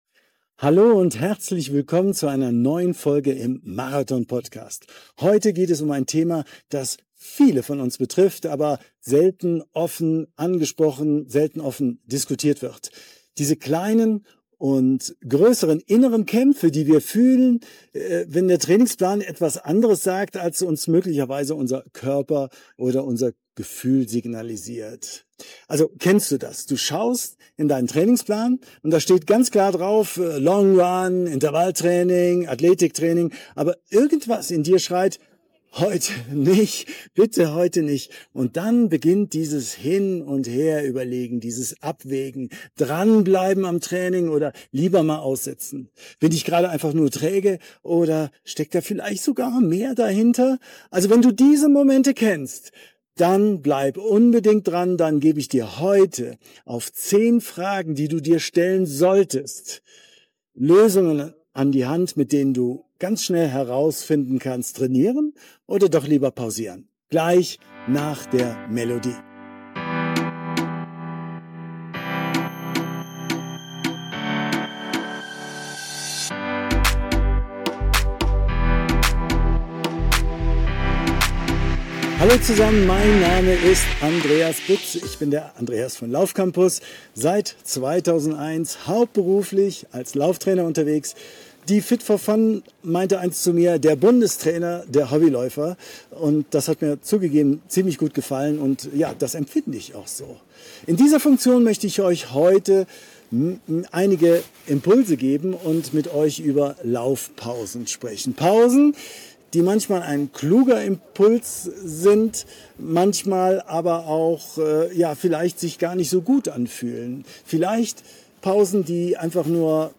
Kleine Audio-Warnung vorab: Du hörst gelegentlich das Knistern meiner Hardshell – echte Outdoor-Vibes eben Die Tonqualität ist nicht perfekt, aber die Gedanken sind es wert, gehört zu werden!